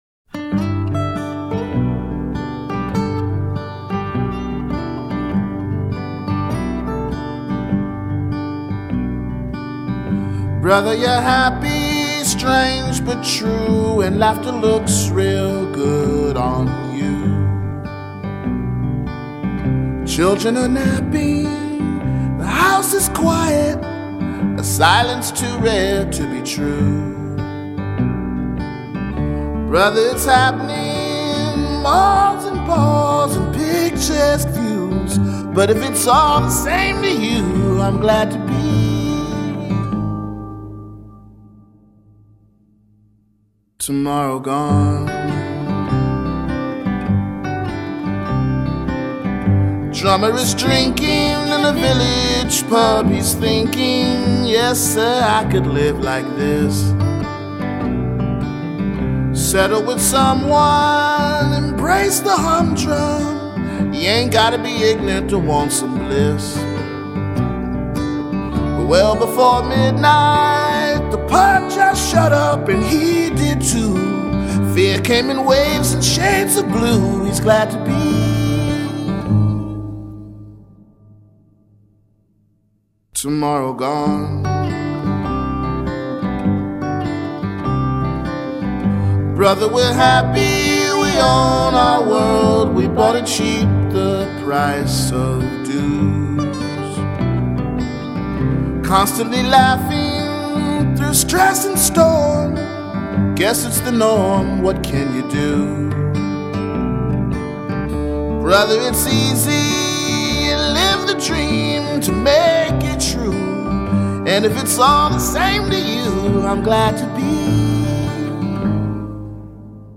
This is a quiet, introspective album